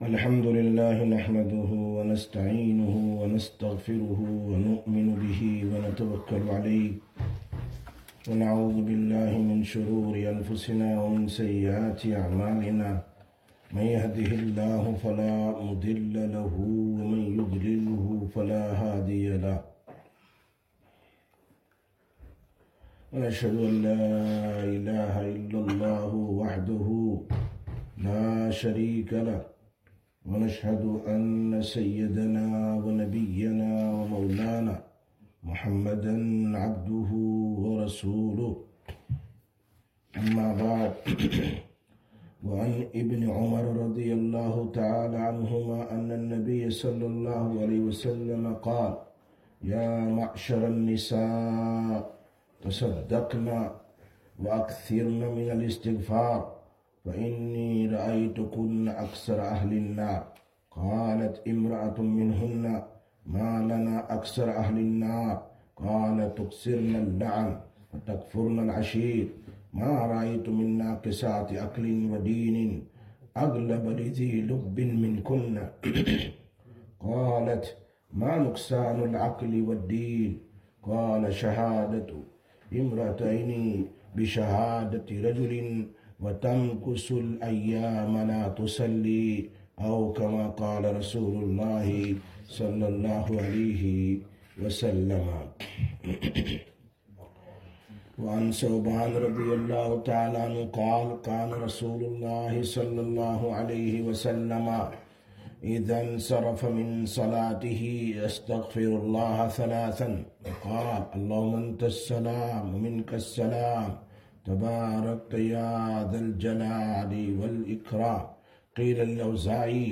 18/03/2026 Sisters Bayan, Masjid Quba